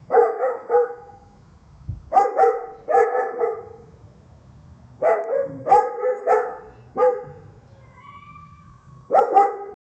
dogs-barking--jut6v6a6.wav